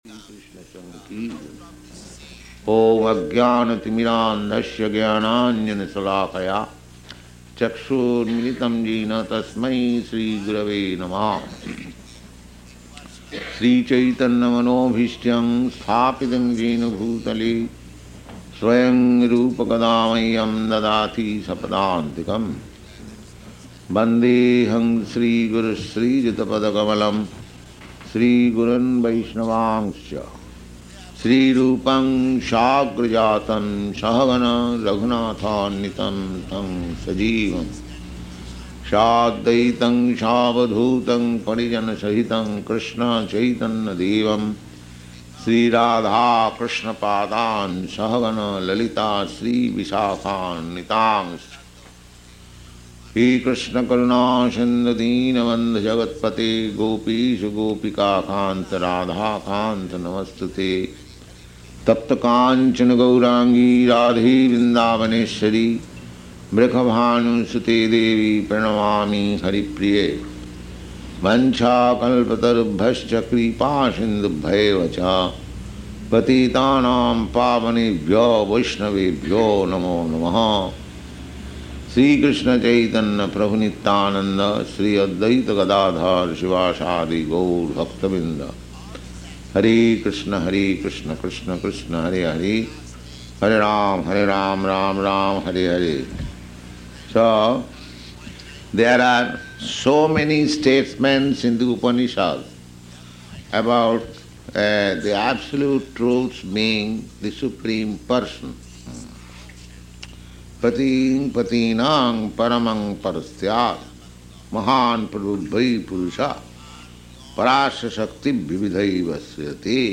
Location: San Francisco
[chants maṅgalācaraṇa prayers]